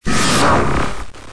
Молнии: